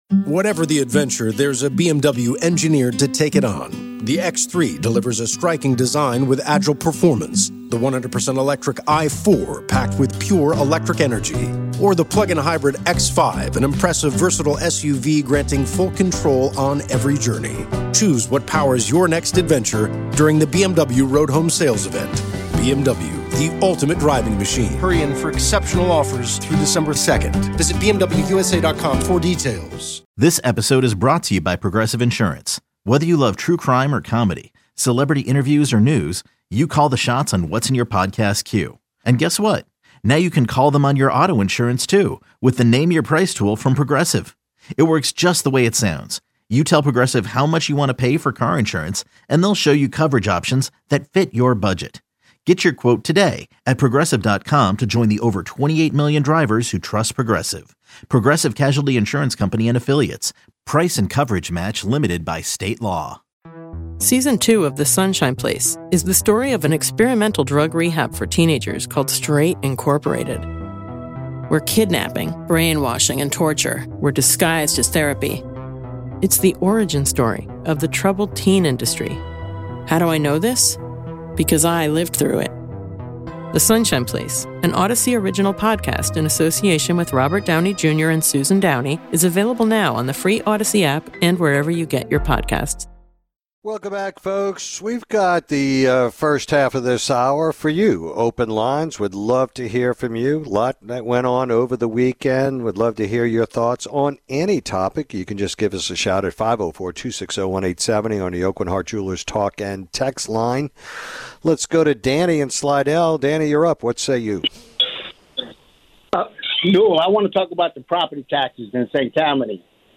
This hour, Newell opens the phone lines, and the callers chime in about the increase in property tax in St. Tammany Parish and anti-Israel protests on college campuses.